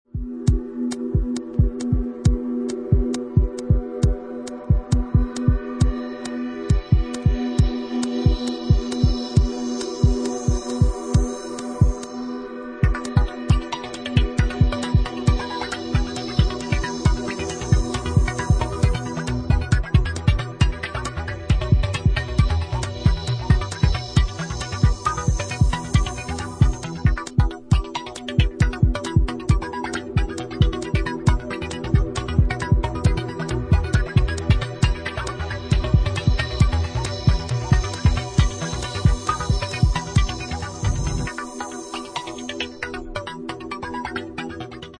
Deep ambient-like electro